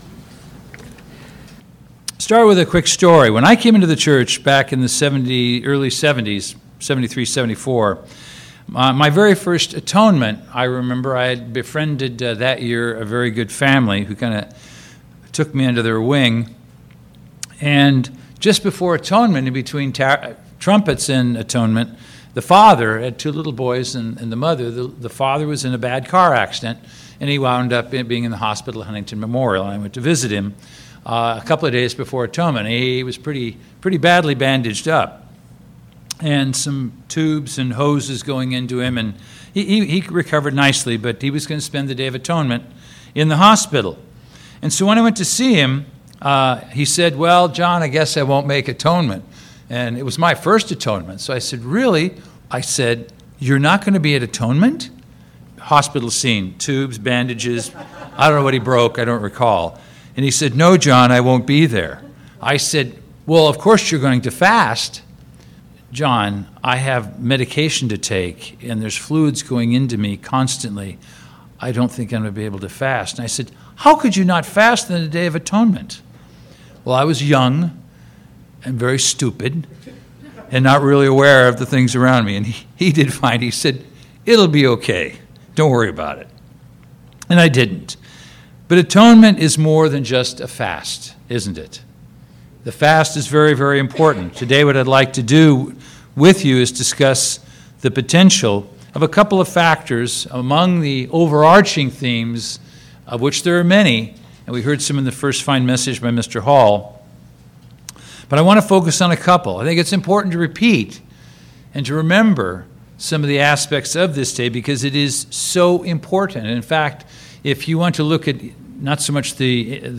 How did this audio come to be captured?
Given in Redlands, CA